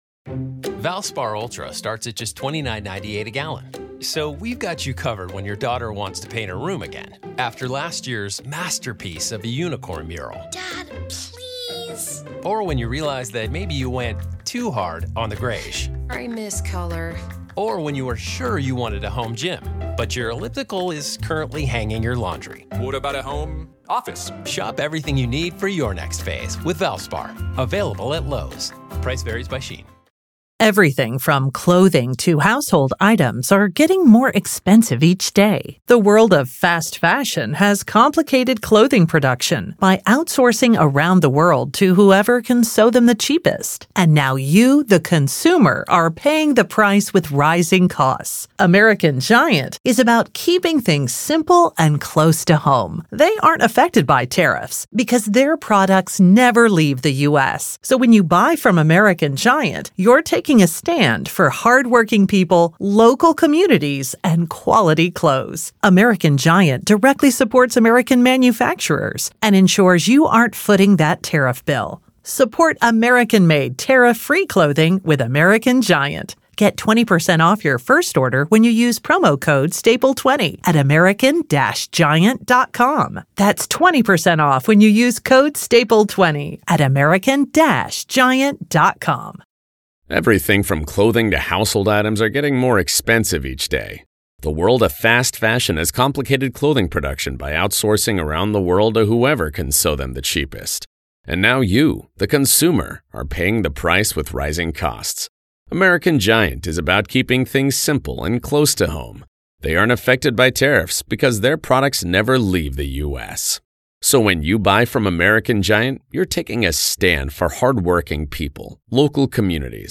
Kohberger Hearing-RAW AUDIO Part 1: Defense Makes Last Stand Before August Trial